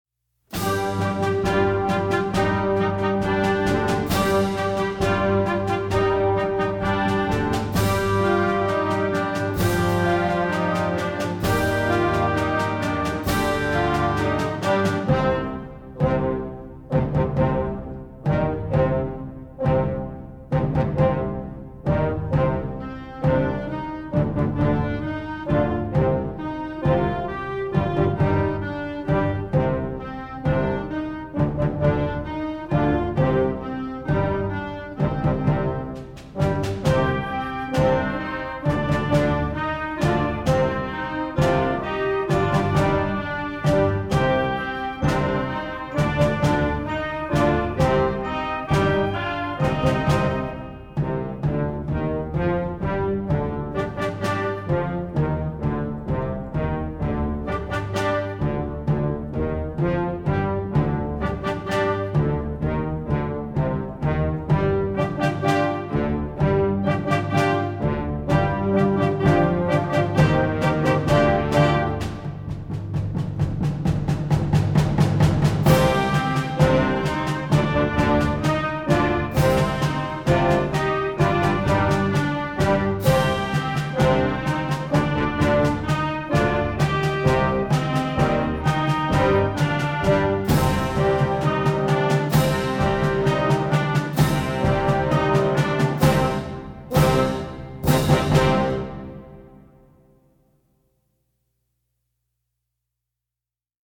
an exciting new contest-style piece for very young groups
with an aggressive, driving feeling.